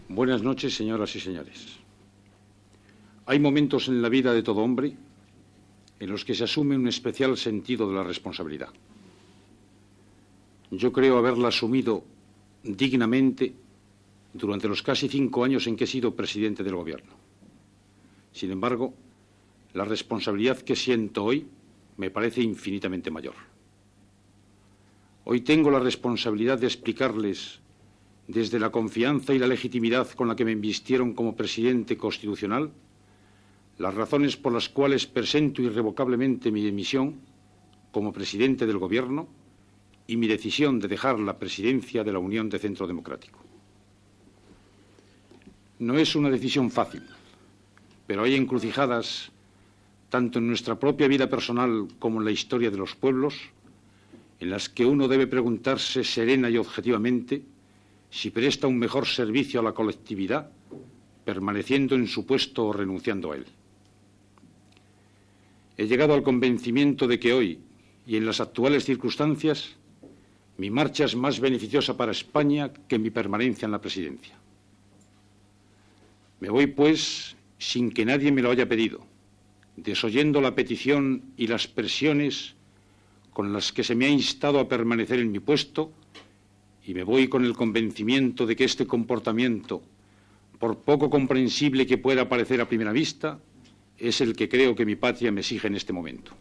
Fragment del discurs del president del govern espanyol Adolfo Suárez en el qual anuncia la seva dimissió
Informatiu